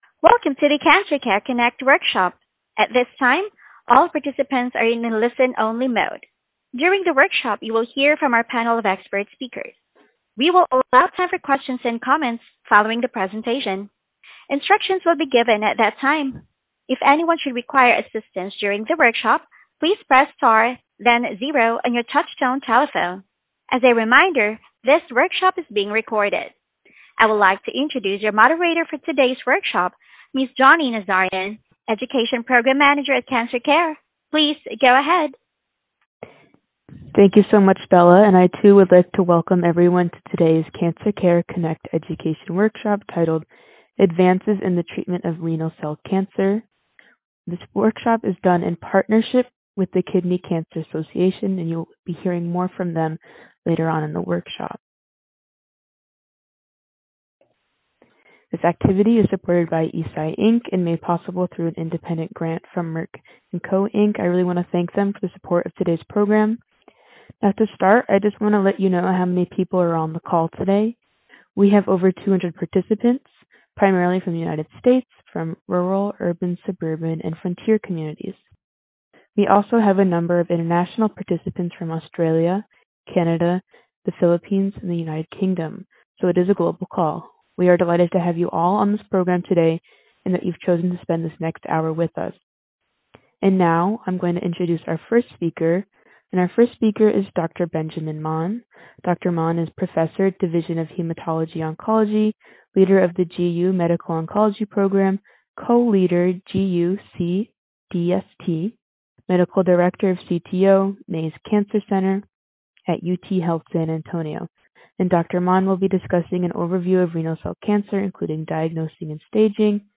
Questions for Our Panel of Experts